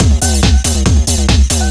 DS 140-BPM B1.wav